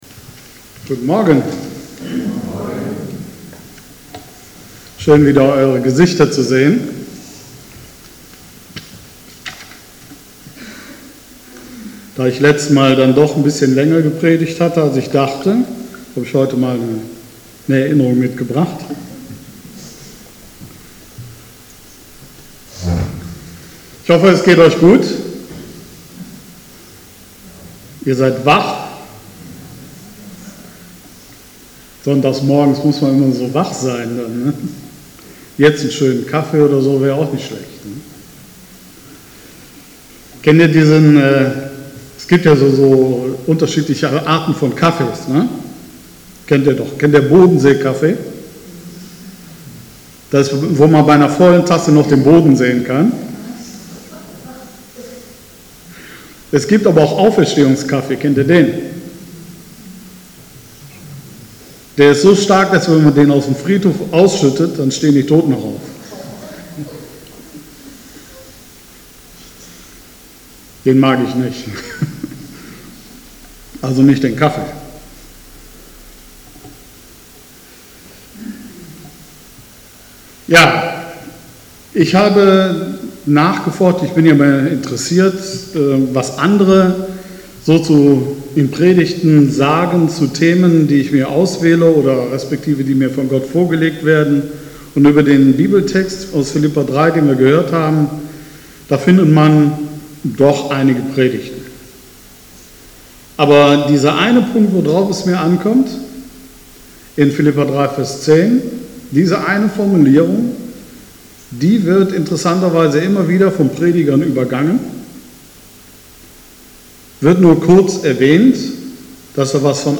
Mein Predigtpodcast